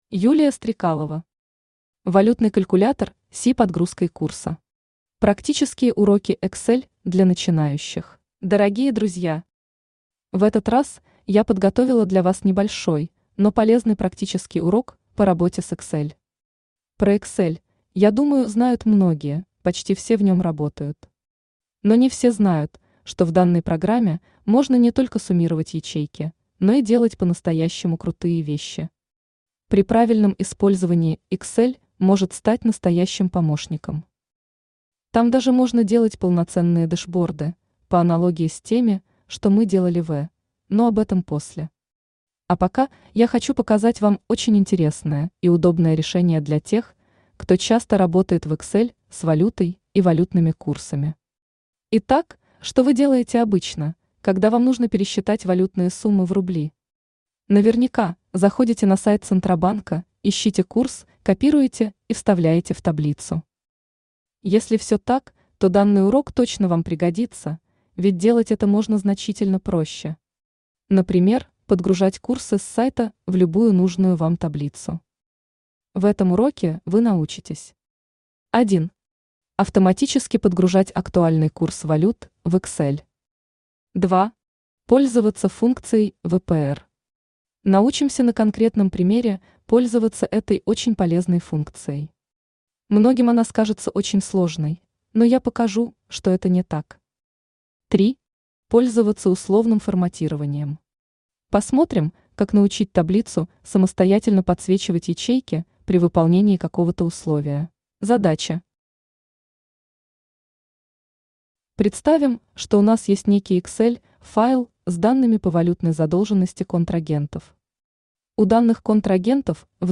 Практические уроки Excel для начинающих Автор Юлия Стрекалова Читает аудиокнигу Авточтец ЛитРес.